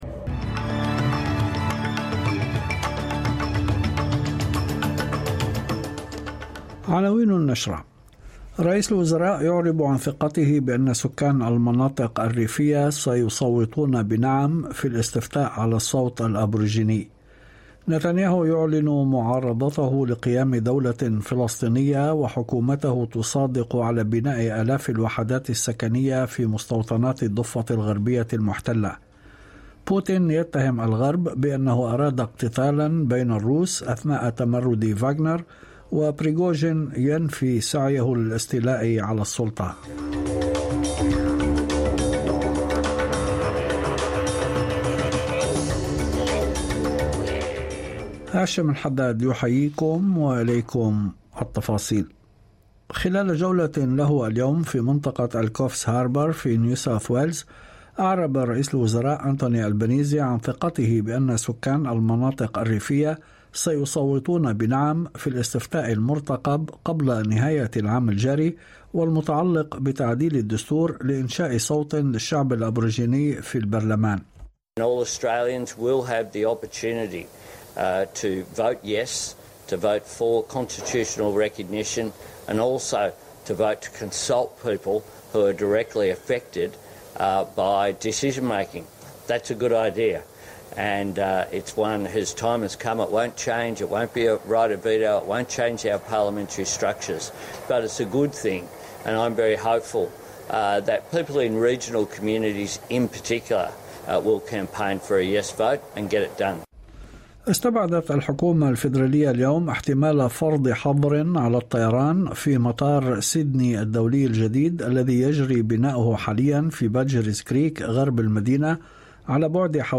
نشرة أخبار المساء 27/06/2023